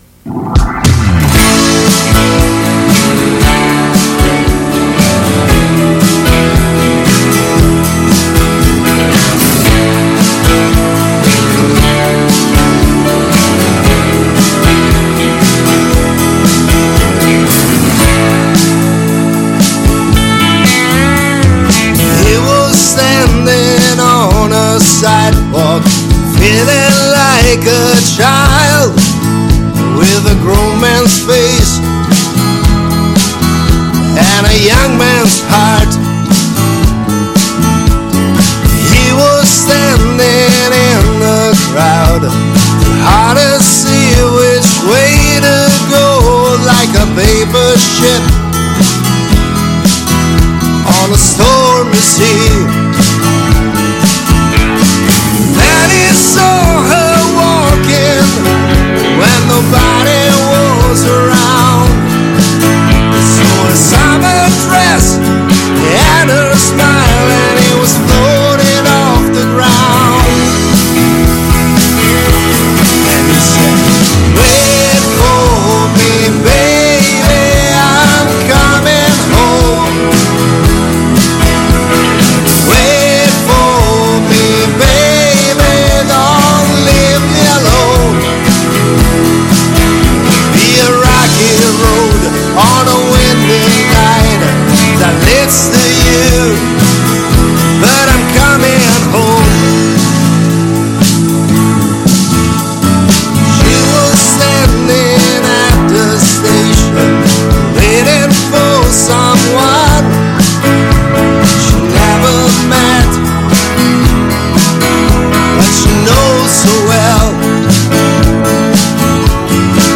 ha eseguito per noi un paio di brani live in acustico.